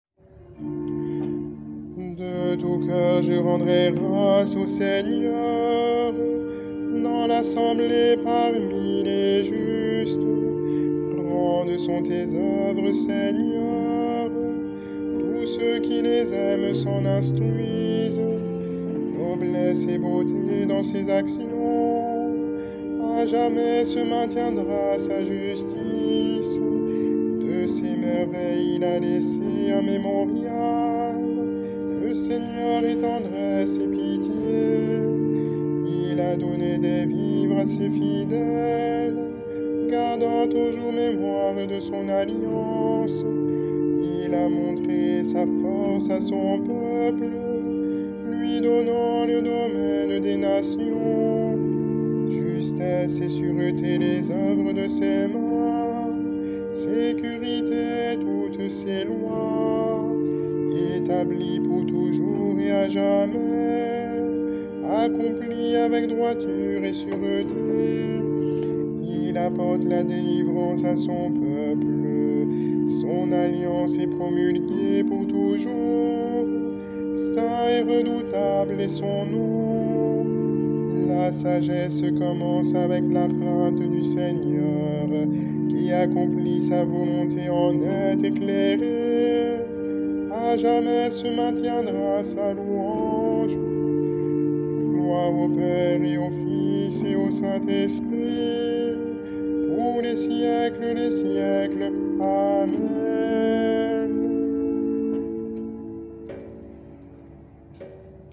3° dimanche de Pâques - Chorale Paroissiale du Pôle Missionnaire de Fontainebleau
Psaume-110-Vepres-Dim-III.mp3